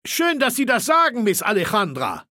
Datei:Maleold01 ms06 goodbye 000681d3.ogg
Fallout 3: Audiodialoge